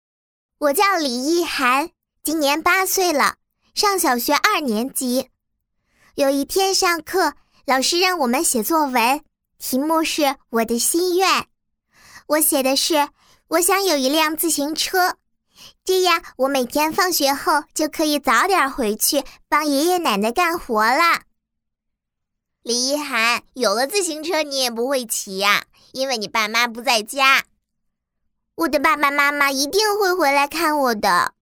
当前位置：首页 > 配音题材 > 童声配音
童声配音即儿童音或者少年音，也叫小孩音。